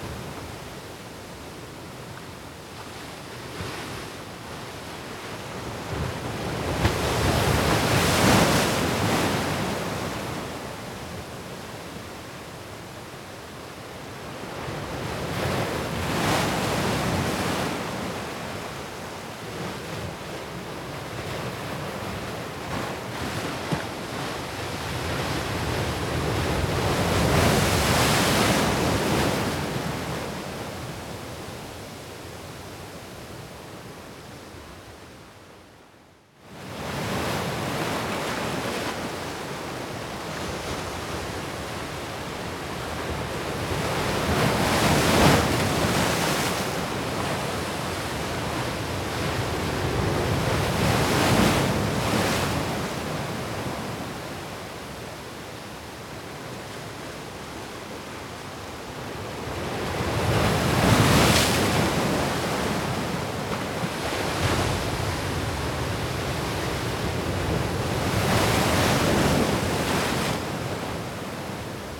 oceanAmbience.wav